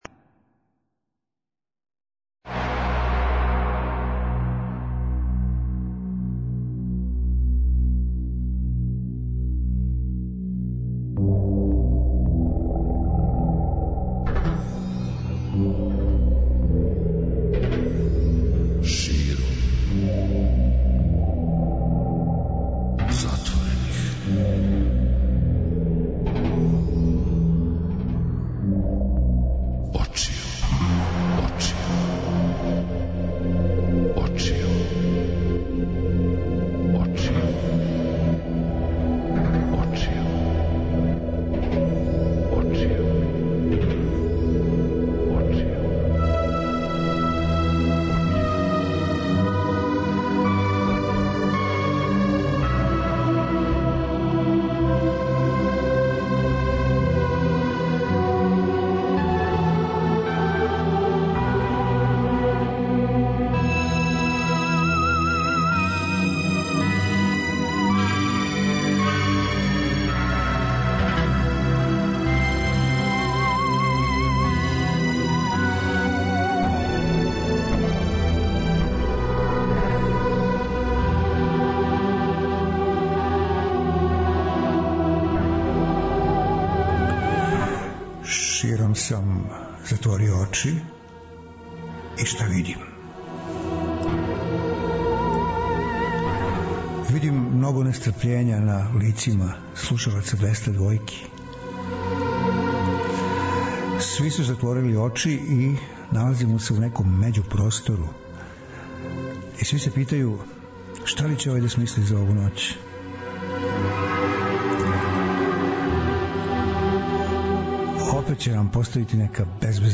Квалитетна музика се подразумева.
преузми : 42.30 MB Широм затворених очију Autor: Београд 202 Ноћни програм Београда 202 [ детаљније ] Све епизоде серијала Београд 202 Летње кулирање Хит недеље Брза трака Домаћице и комшинице Топ листа 202